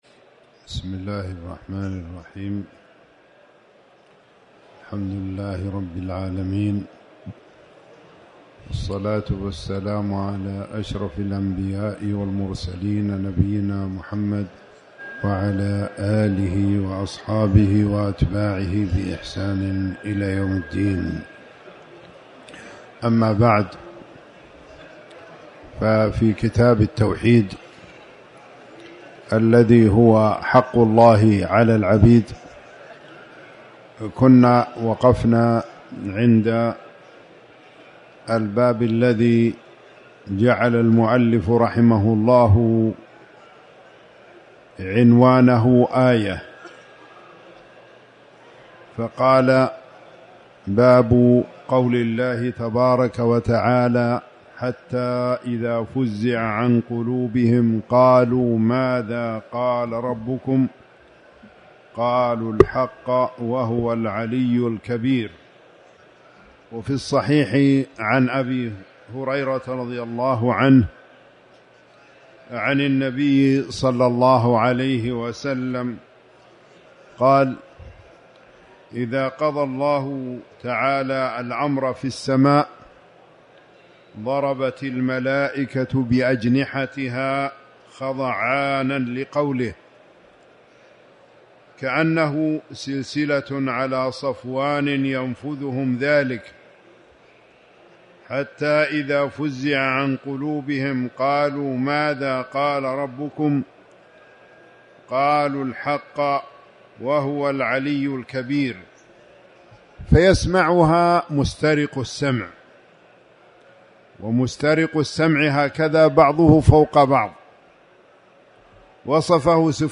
تاريخ النشر ٢٥ رمضان ١٤٣٩ هـ المكان: المسجد الحرام الشيخ